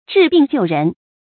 注音：ㄓㄧˋ ㄅㄧㄥˋ ㄐㄧㄨˋ ㄖㄣˊ